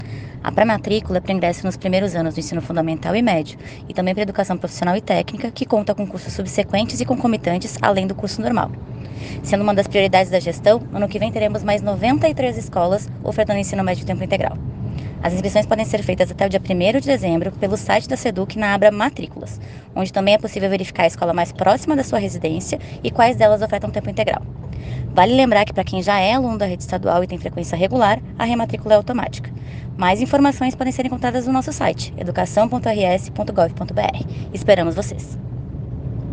Secretária adjunta Stefanie Eskereski explica sobre o processo de matrículas na rede estadual